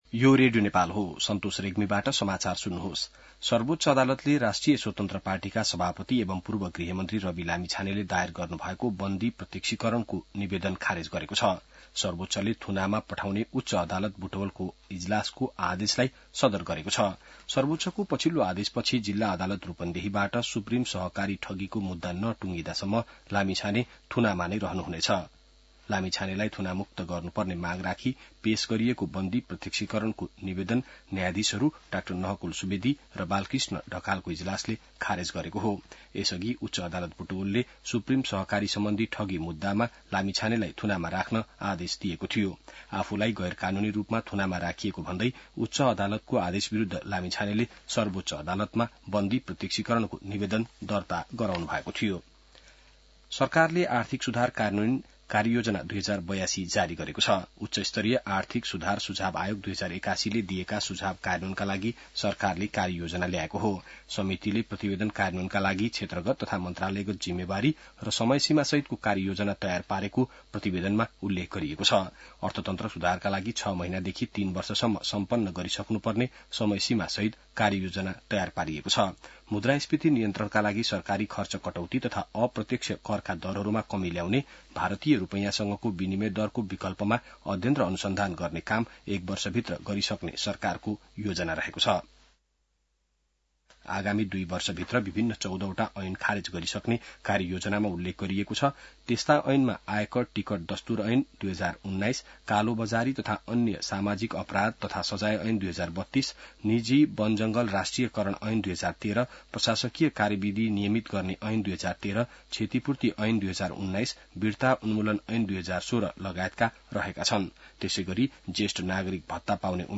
बिहान ६ बजेको नेपाली समाचार : १० जेठ , २०८२